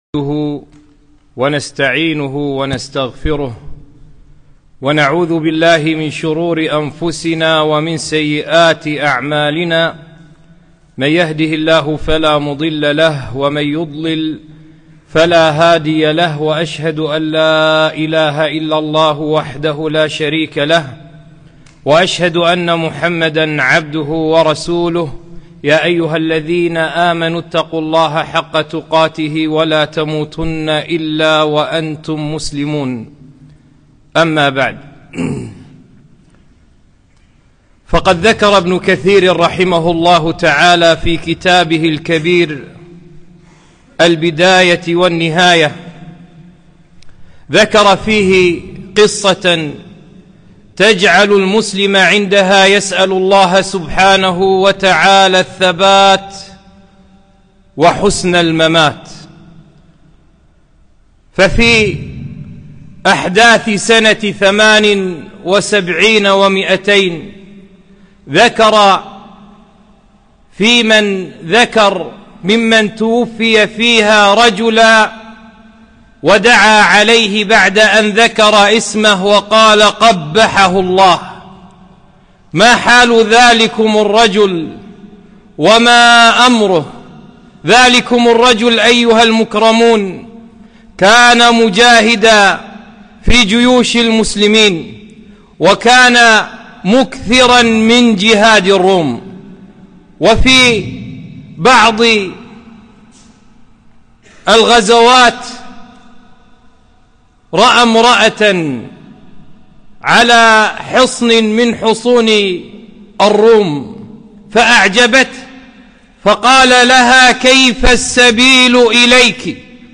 خطبة - ( أسباب الثبات على الدين )